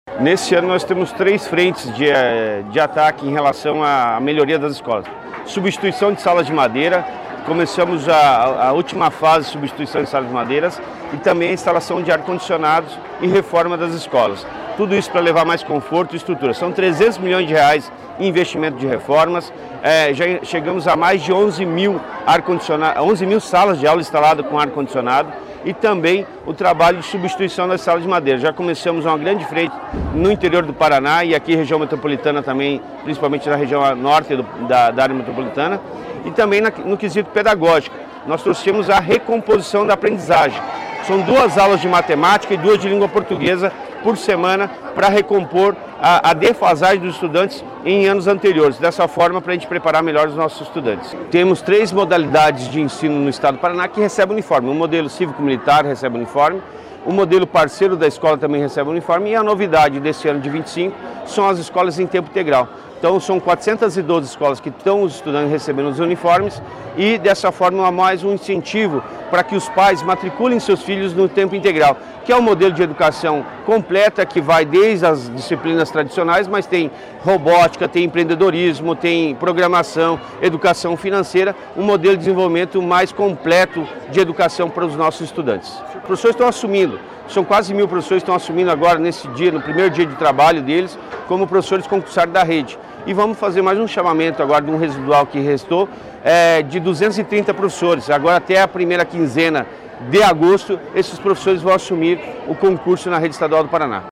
Sonora do secretário da Educação, Roni Miranda, sobre a volta às aulas